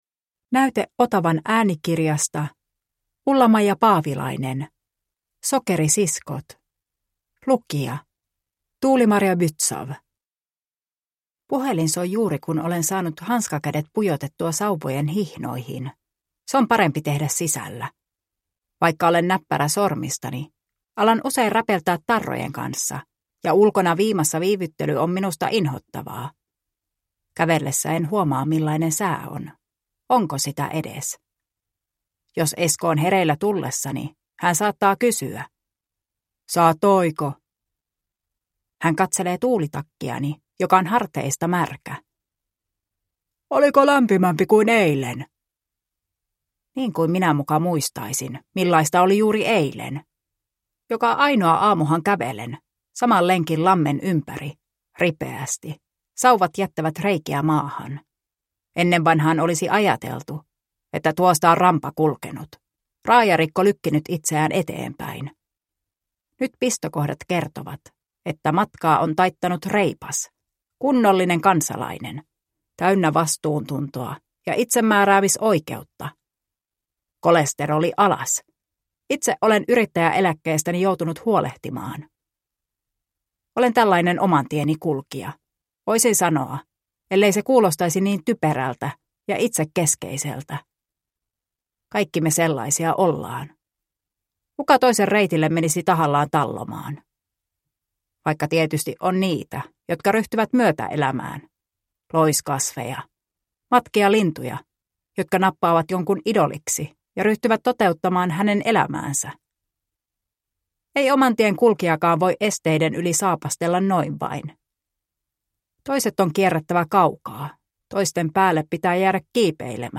Sokerisiskot – Ljudbok – Laddas ner